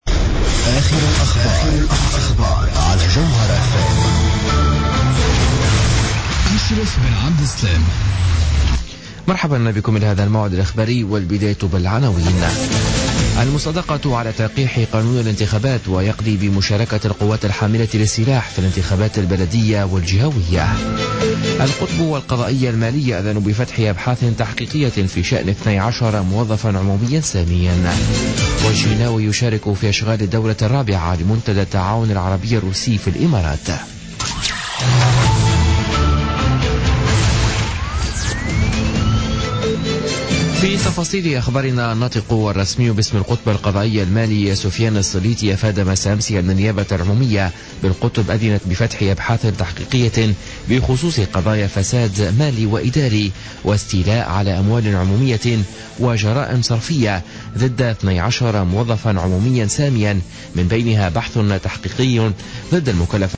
نشرة أخبار منتصف الليل ليوم الاربعاء غرة فيفري 2017